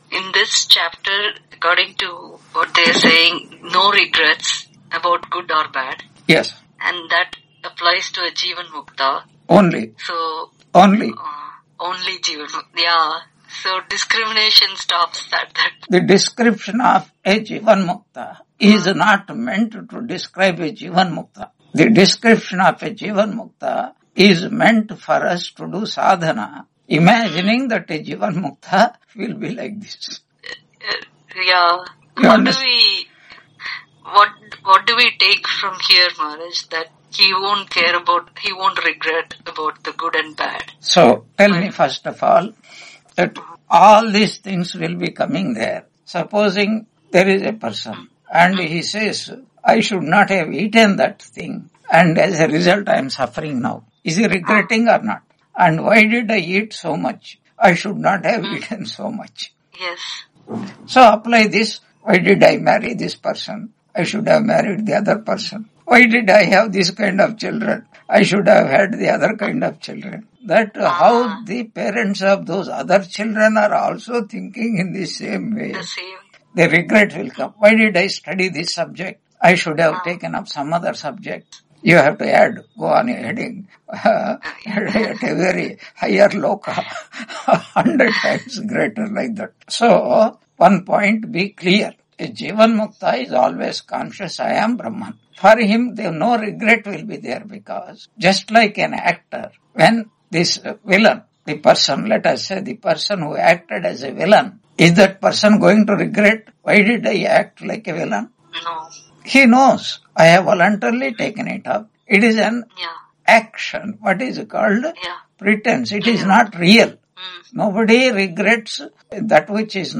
Taittiriya Upanishad Lecture 95 Ch2.9 on 11 March 2026 Q&A - Wiki Vedanta